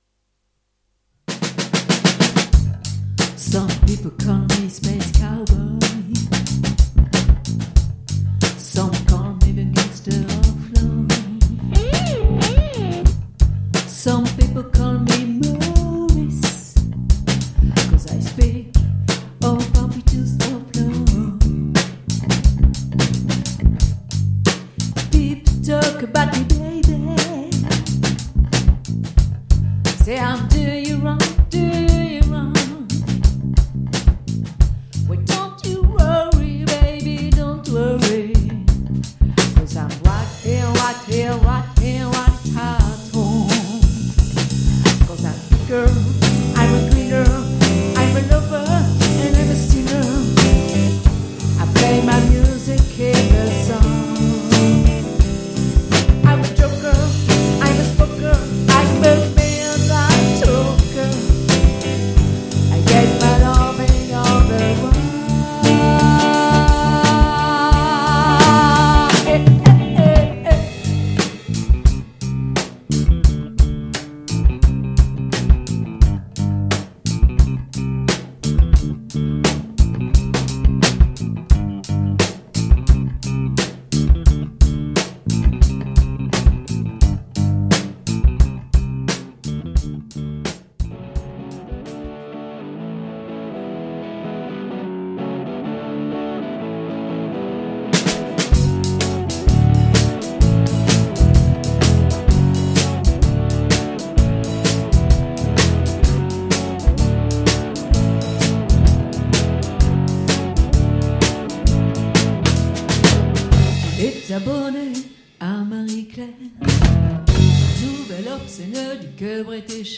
Chant
Basse
Vous faire vibrer et vous offrir un moment de bonheur, rempli de groove, dans une ambiance festive et entraînante, idéale pour toutes les générations.
Batterie